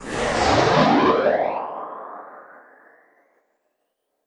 swoosh-sound.wav